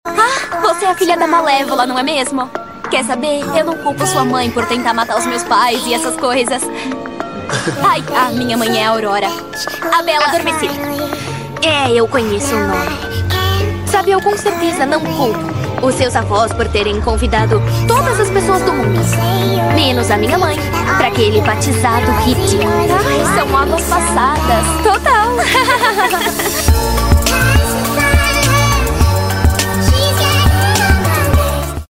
a risada falsa kakakakaka // sound effects free download